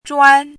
怎么读
zhuān
zhuan1.mp3